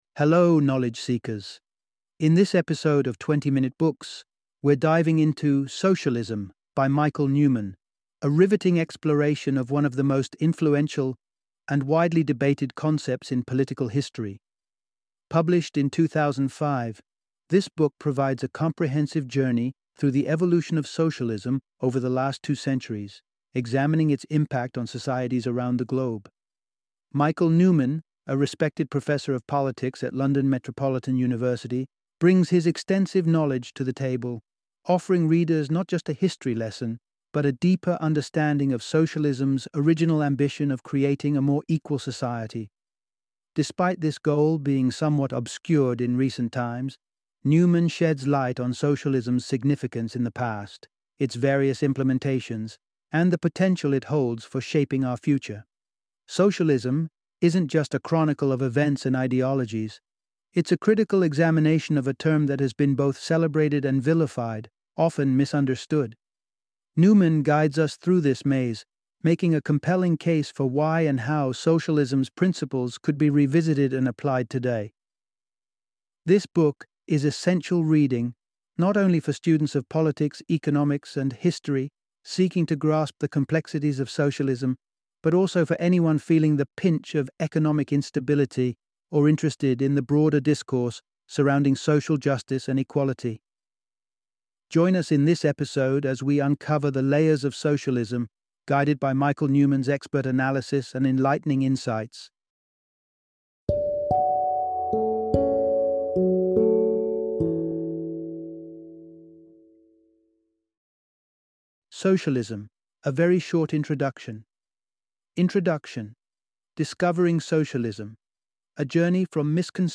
Socialism - Audiobook Summary